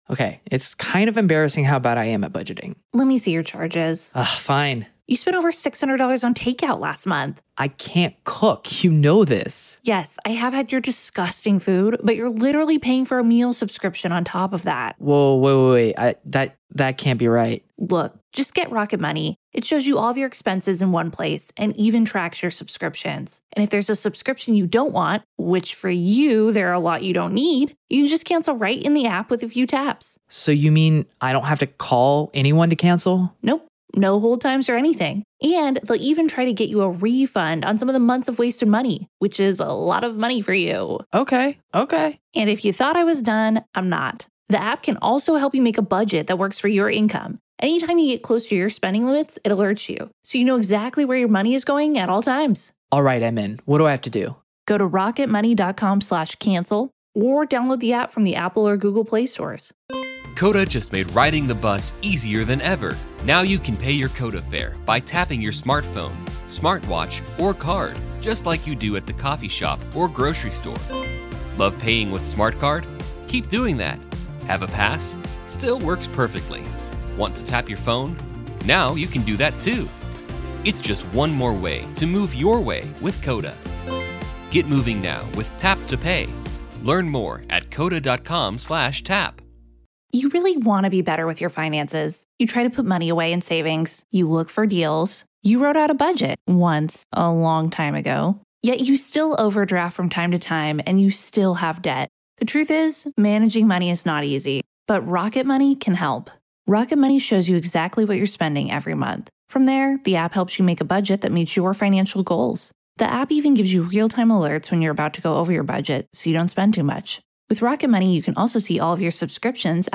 The Trial Of Alex Murdaugh | FULL TRIAL COVERAGE Day 19 - Part 5